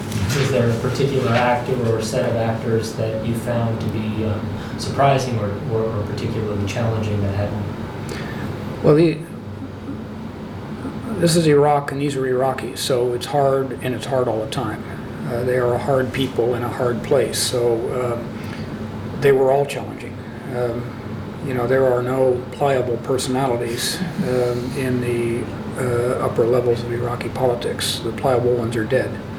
He describes here his early dealings with Iraqi politicians. Date: September 9, 2010 Participants Ryan Crocker Associated Resources Ryan Crocker Oral History The George W. Bush Oral History Project Audio File Transcript